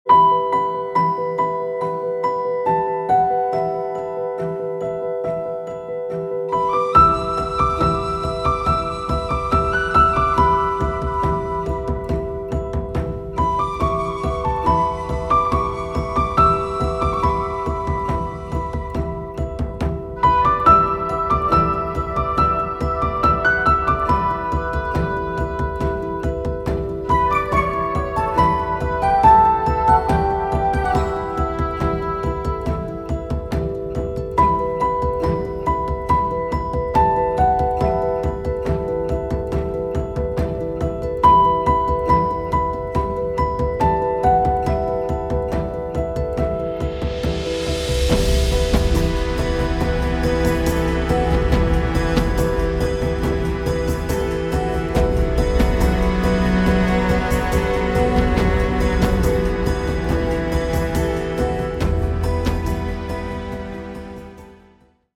Heroic Theme